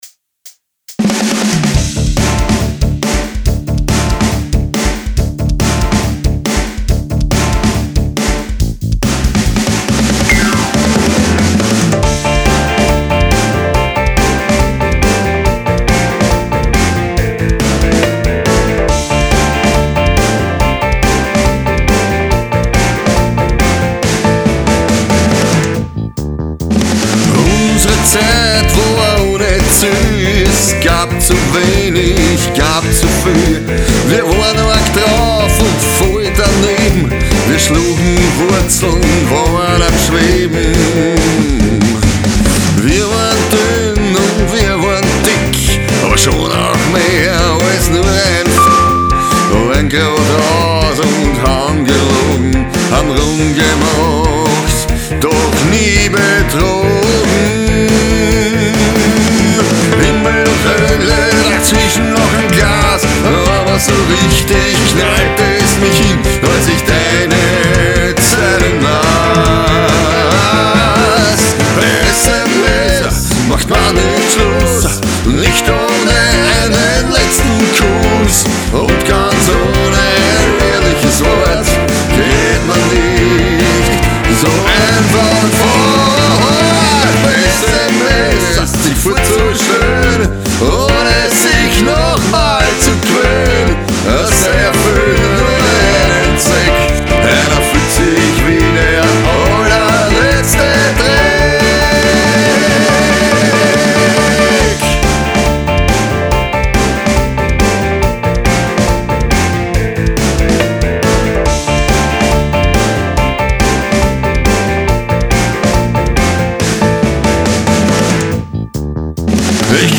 PB mit PEEP ZENSUR und GESANG gesamt MIX 1